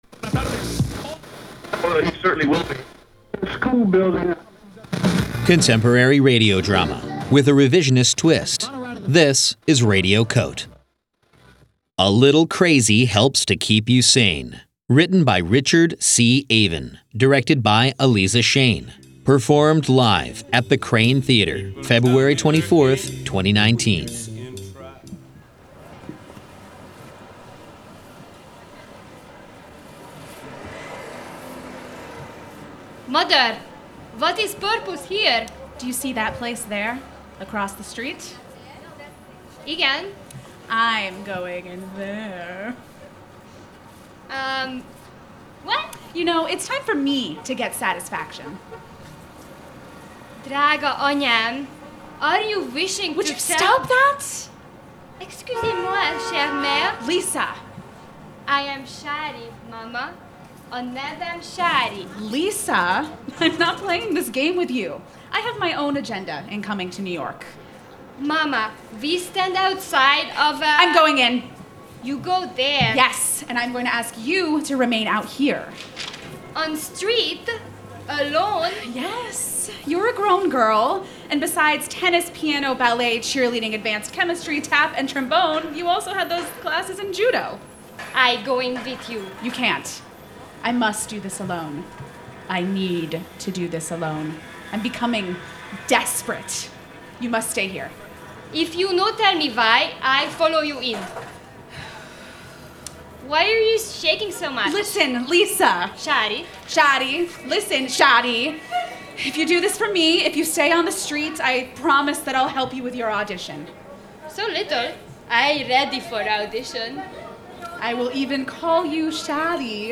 performed for Radio COTE: 24-hour Newsroom in the FRIGID Festival, February 24, 2019